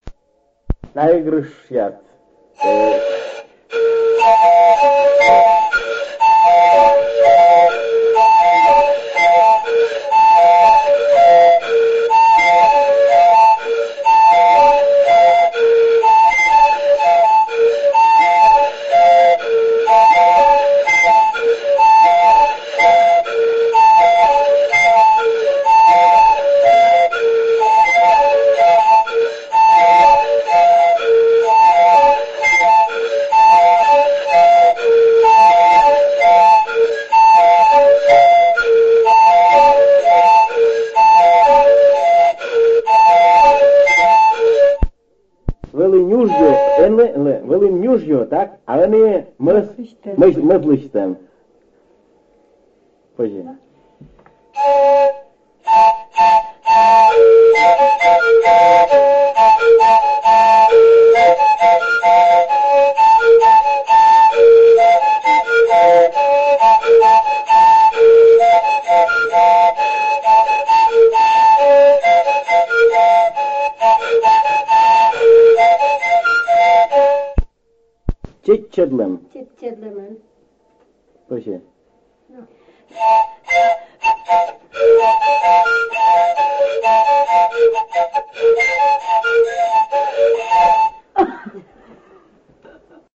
на трехствольных флейтах «куима чипсан» в исполнении дуэта из села Занулье Прилузского района Республики Коми
Место фиксации: Республика Коми, Прилузский район, село Занулье